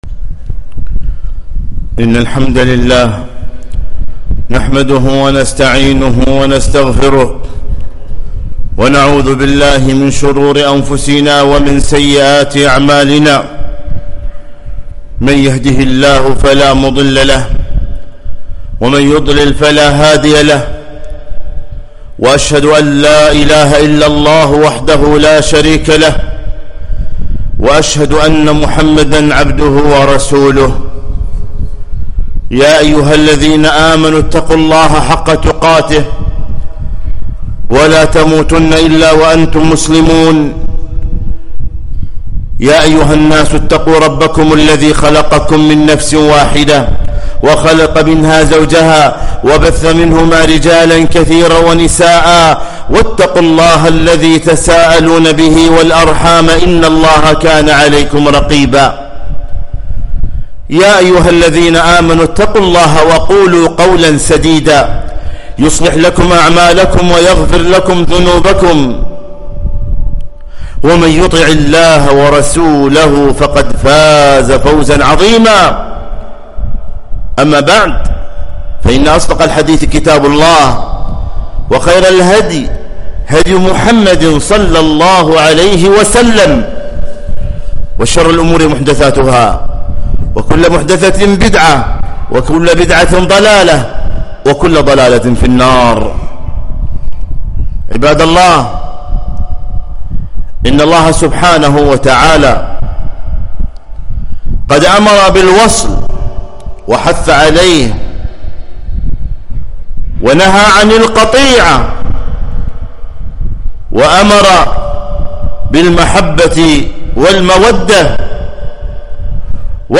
خطبة - (صلة الرحم تعمر الديار وتزيد في الأعمار)